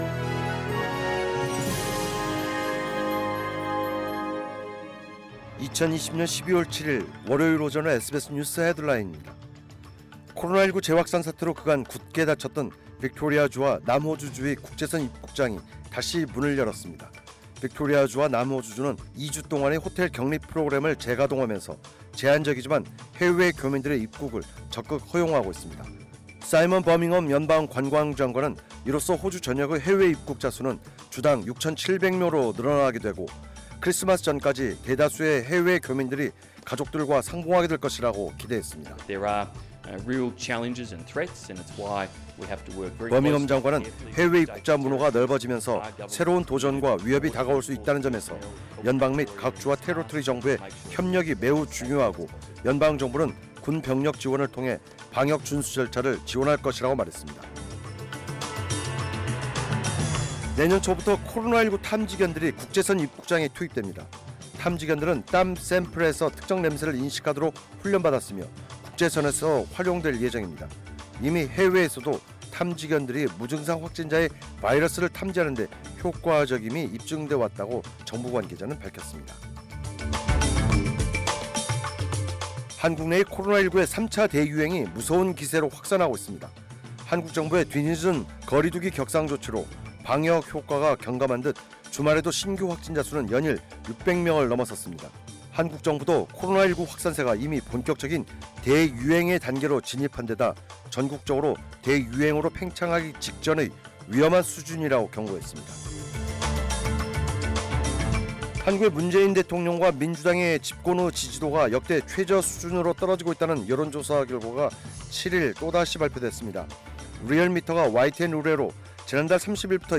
2020년 12월 7일 월요일 오전의 SBS 뉴스 헤드라인입니다.